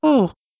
02_luigi_panting.aiff